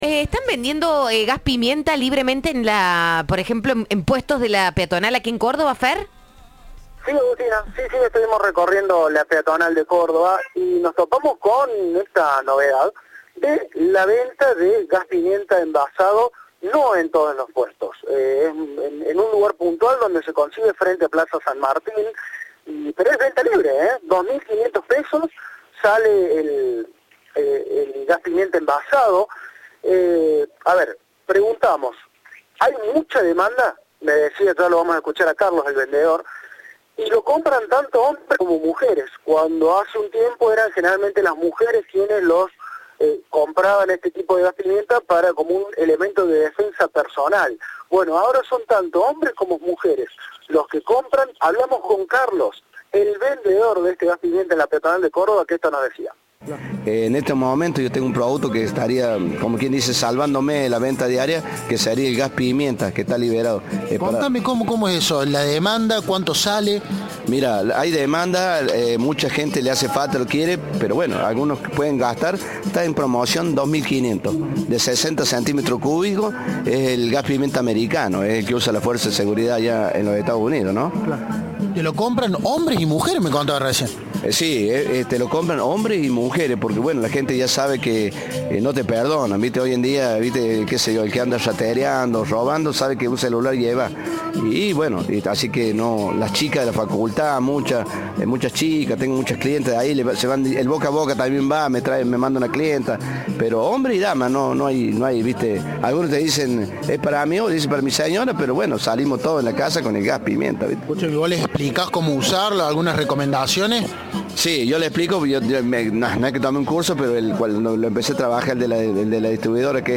El comerciante los oferta a $2.500 por unidad y aseguró ante Cadena 3 que vende cerca de 30 unidades por día.